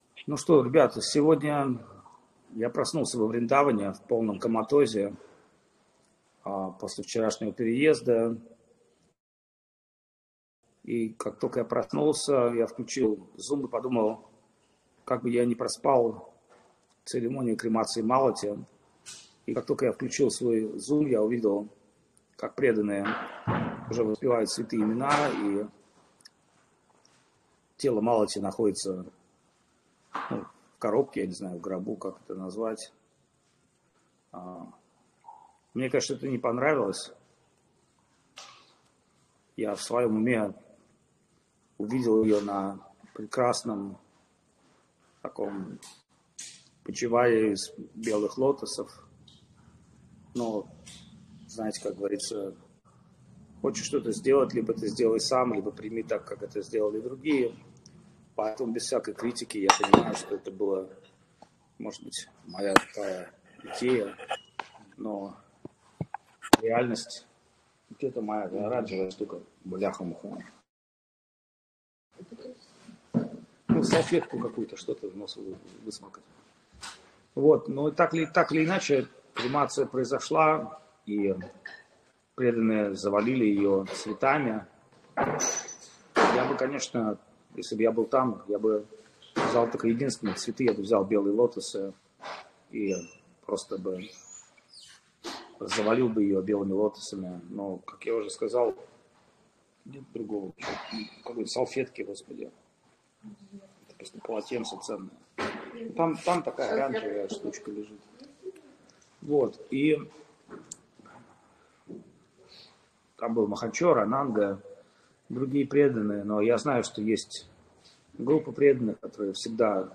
Вриндаван Дхама, Индия